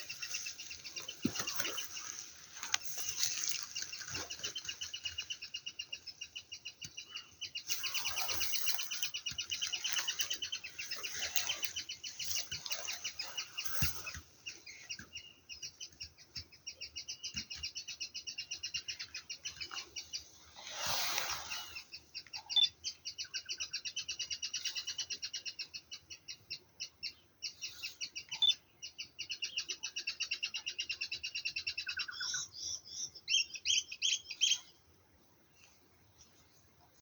Colilarga (Sylviorthorhynchus desmurii)
Fase de la vida: Adulto
Localidad o área protegida: Villa La Angostura
Condición: Silvestre
Certeza: Observada, Vocalización Grabada
COLILARGA.mp3